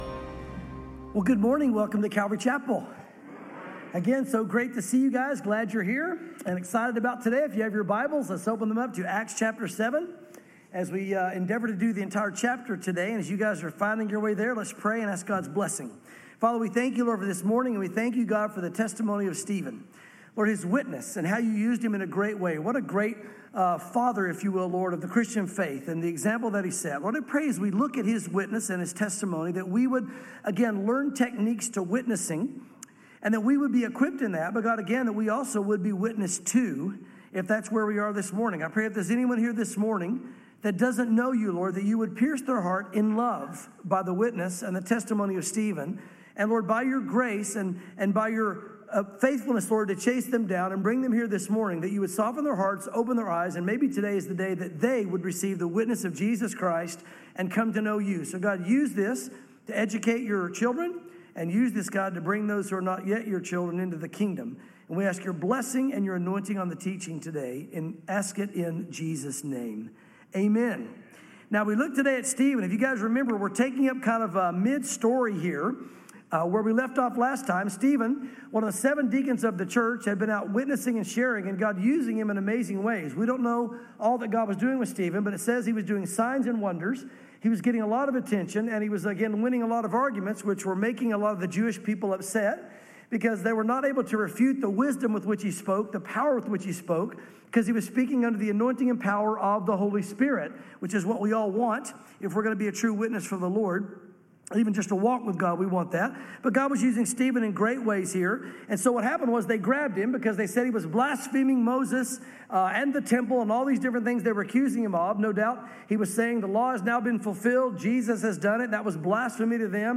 sermons Acts 7 | Stephen - The First Martyr (Part 2 of 2)